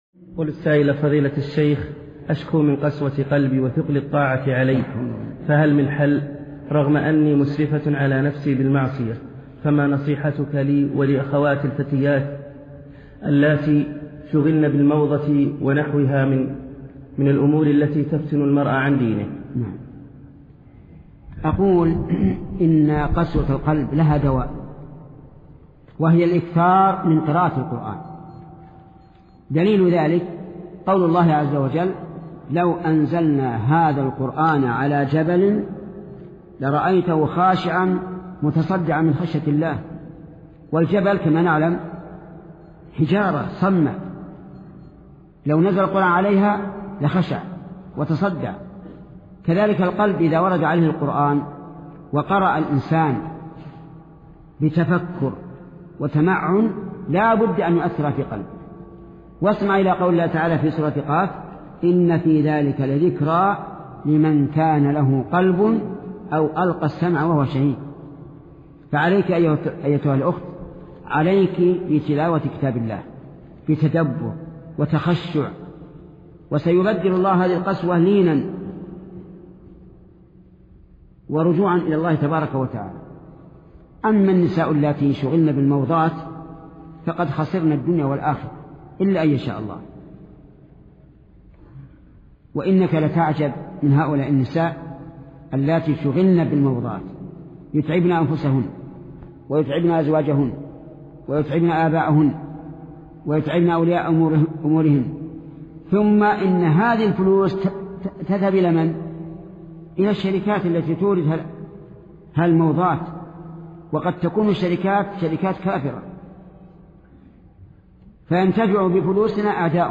محاضرا لشيخ محمد بن صالح العثيمين بعنوان علاج قسوة القلب وثقل الطاعة